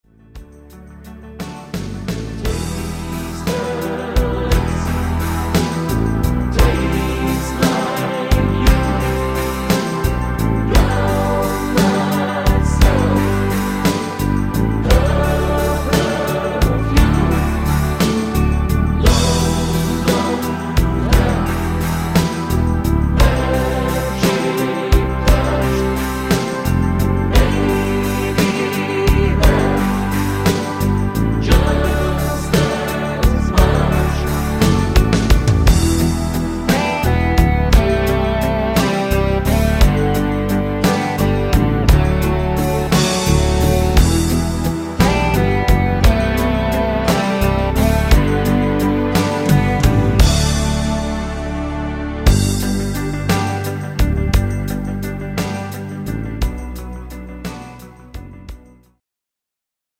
transpose minus 3